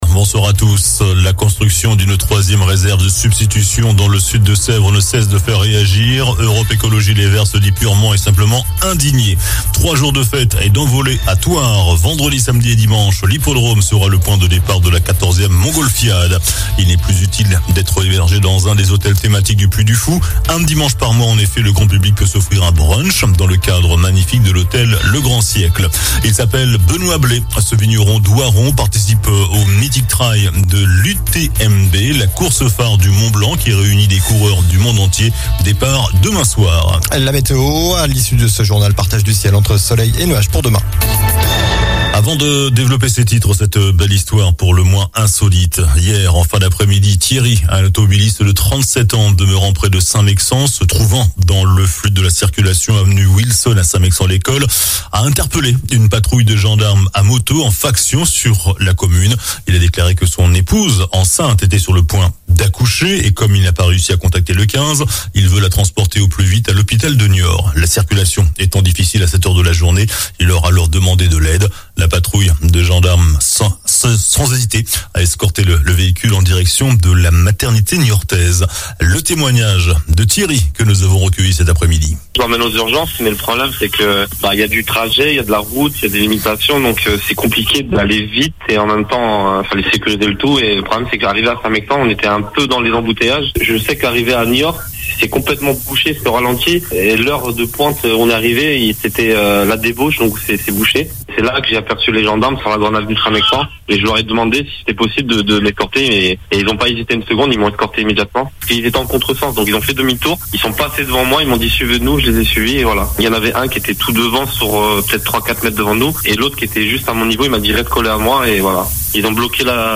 JOURNAL DU JEUDI 31 AOÛT ( SOIR )